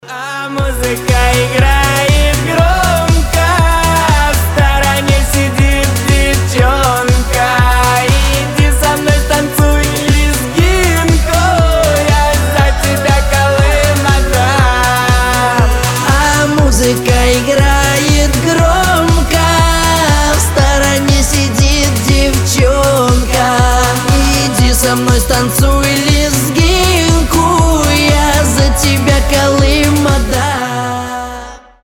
Танцевальные рингтоны
Зажигательные , Позитивные , Дуэт